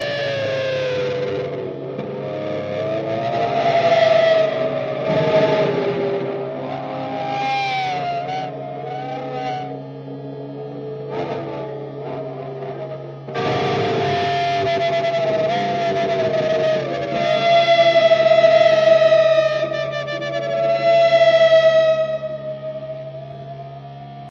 He played some electric guitar very slowly bending strings up or down, into some reverb or delay (or both), some gain on the signal makes it sound a little hot as well. It sounds like humpback whales, but more distorted like guitar overdrive+delay+reverb.